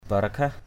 /ba˨˩-ra-kʰah/ (d.) sự khoan hồng, bao dung. manuis pan akaok seng hu barakhah mn&{X pN a_k<K x$ h~% brAH làm thủ trưởng phải có sự bao dung.
barakhah.mp3